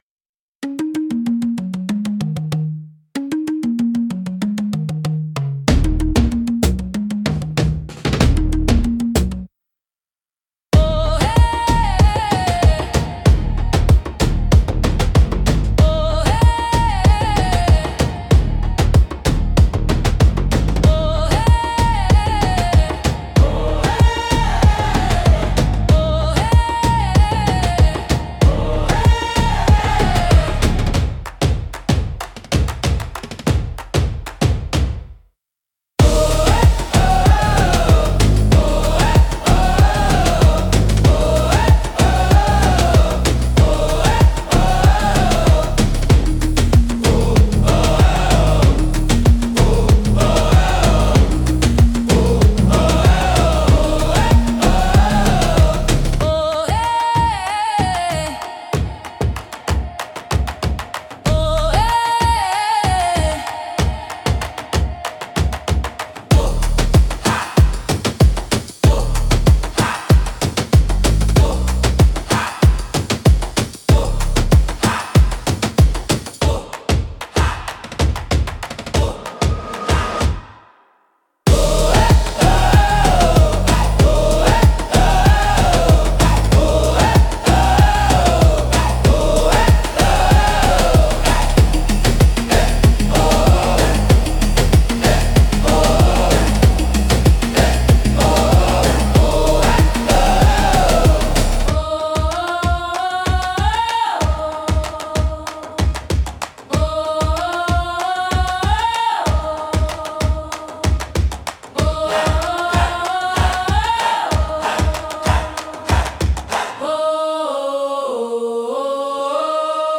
オリジナルのトライバルは、民族的な打楽器やリズムが中心となり、神秘的で原始的な雰囲気を持つジャンルです。
繰り返しのリズムと独特のメロディが、古代的かつエネルギッシュな空気感を作り出します。
迫力と神秘性が共存するジャンルです。